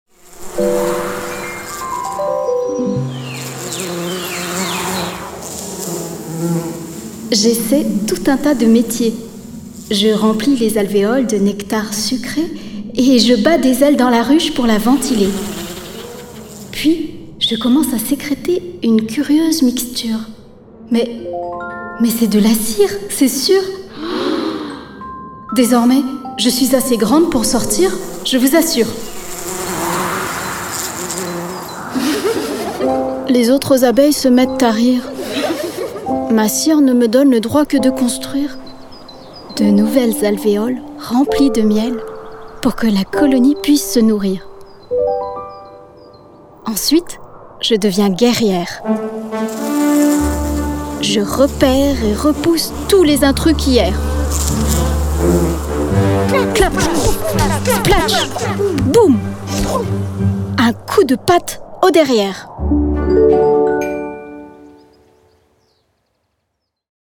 Voix off
Voix pour le musée "la maison de l'abeille noire" conte sonore